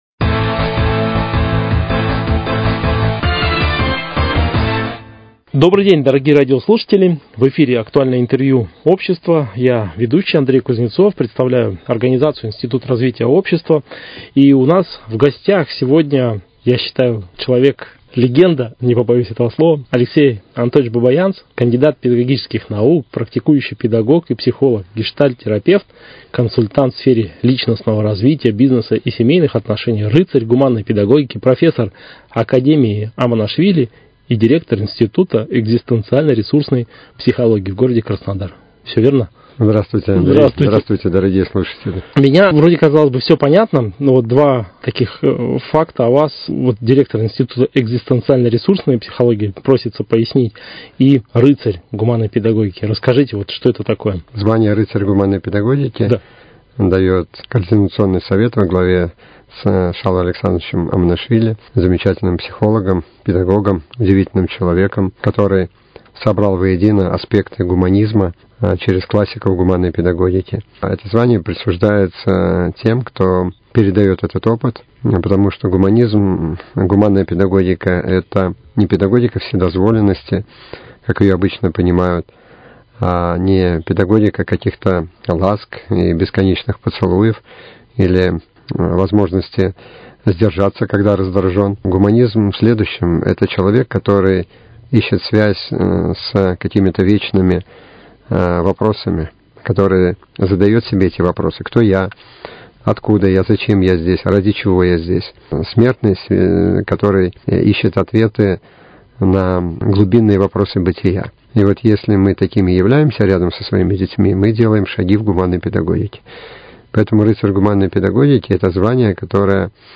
Актуальное интервью: О самосовершенствовании человека